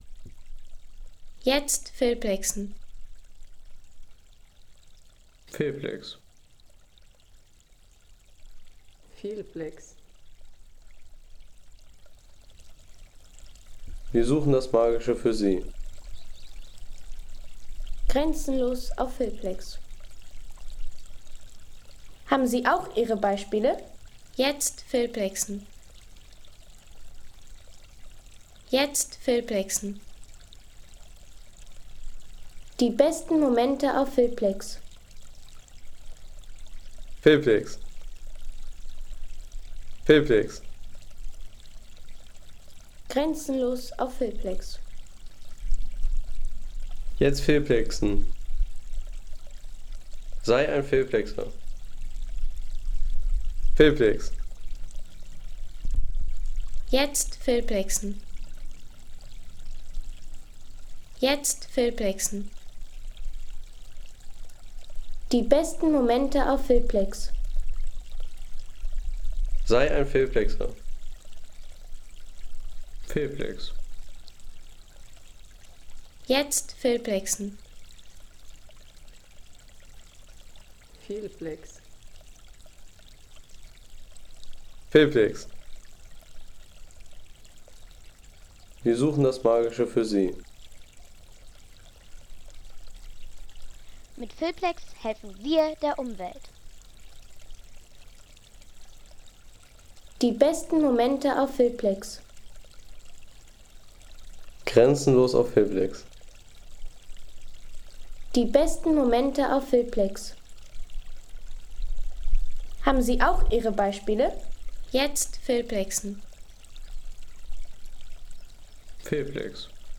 Erholungsgenuss für alle, wie z.B. diese Aufnahme: Bach auf Roque Nublo
Bach auf Roque Nublo